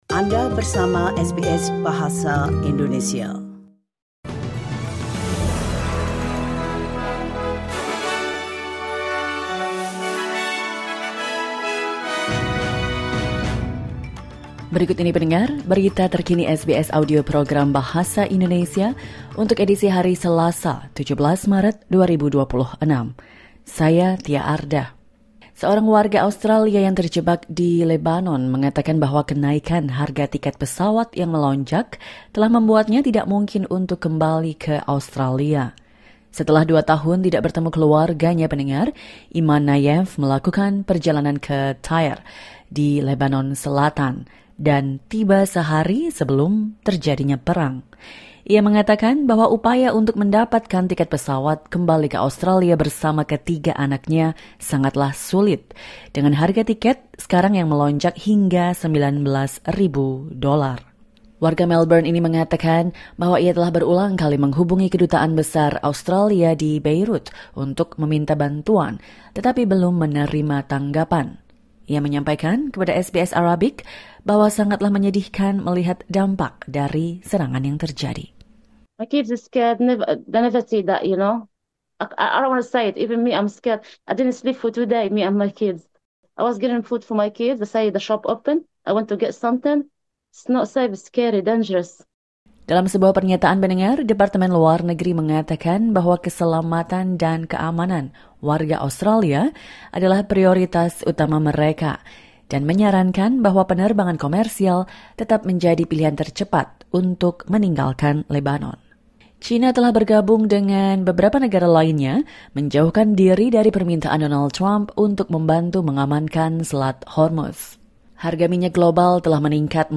Latest News SBS Audio Indonesian Program - Tuesday 17 March 2026